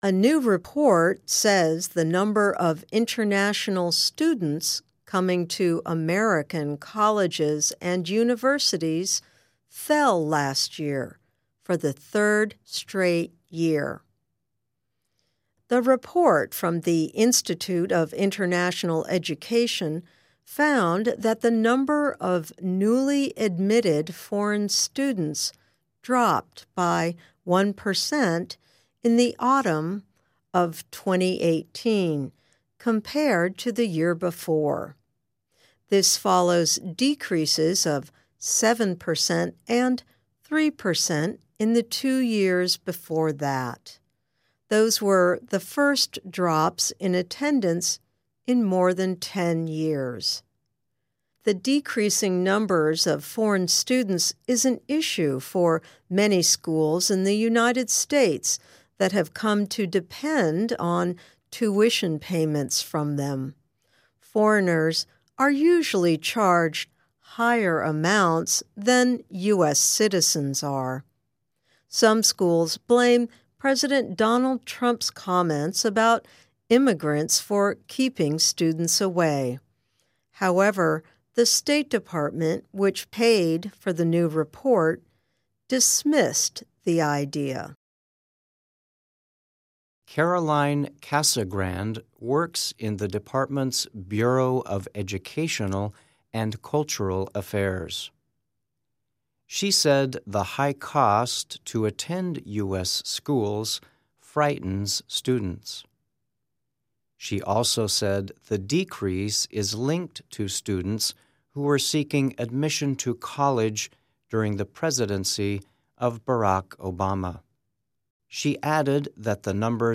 慢速英语:报告称来美留学生人数再次下降